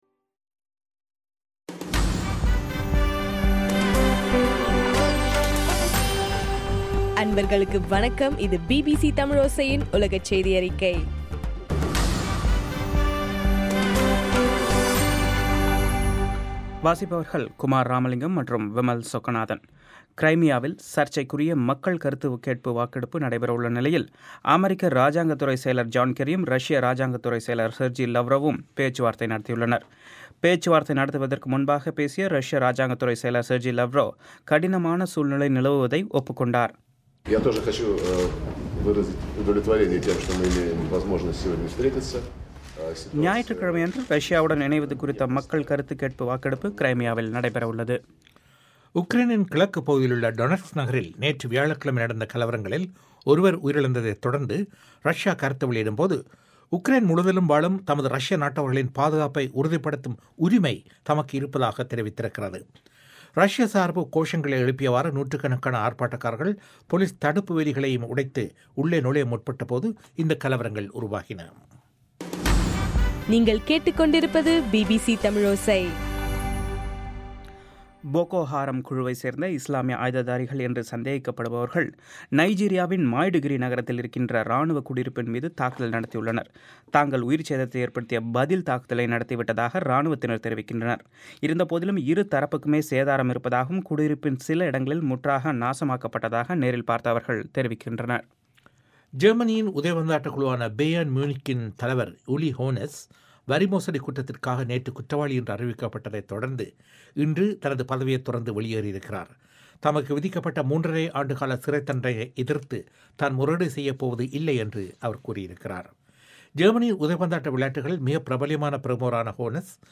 மார்ச் 14, 2014 பிபிசி தமிழோசையின் உலகச் செய்திகள்